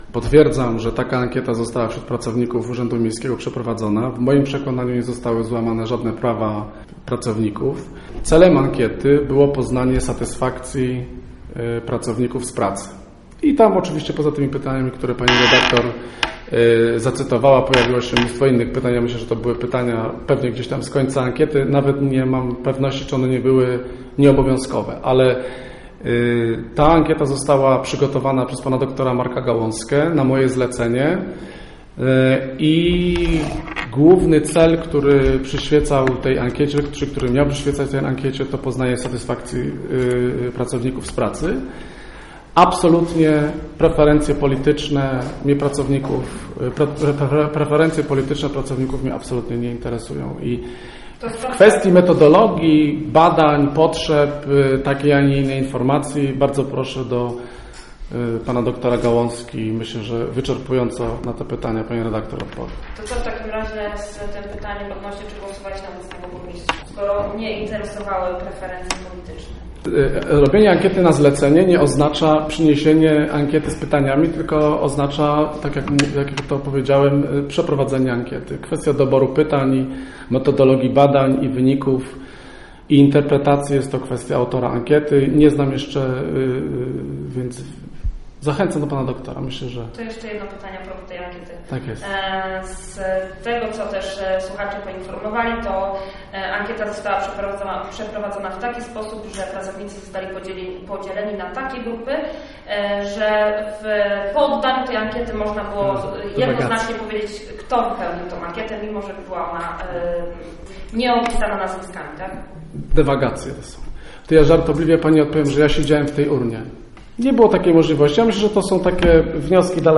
Podczas ostatniej konferencji prasowej postanowiliśmy zatem zapytać Burmistrza Roberta Luchowskiego czy rzeczywiście fakt przeprowadzenia takiej ankiety wśród pracowników ratusza miał miejsce i jakie były jej cele?